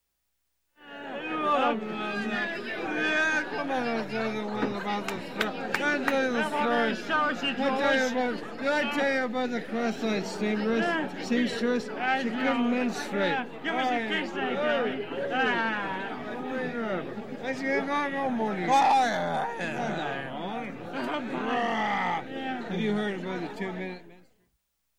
Звуки пьяного человека
Два пьяных мужика пытаются говорить в баре